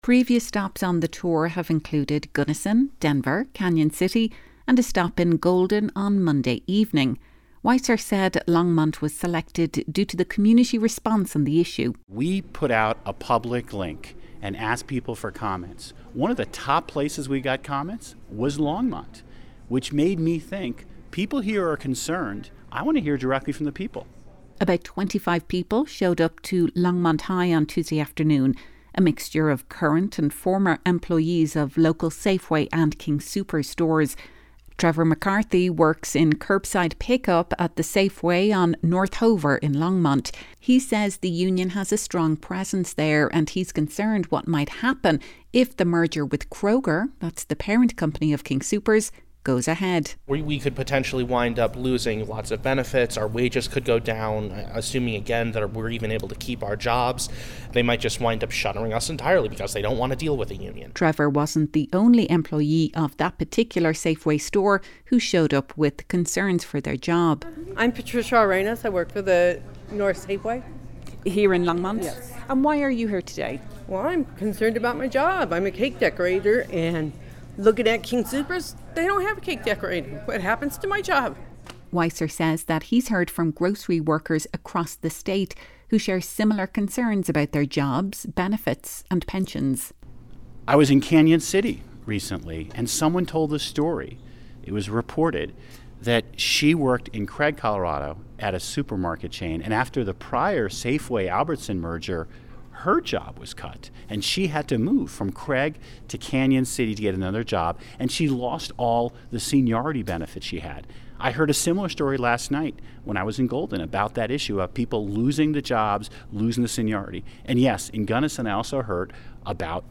Weiser was in Longmont Tuesday as part of his state-wide listening tour.
LongmontGroceryMergerMeetingKGNU.mp3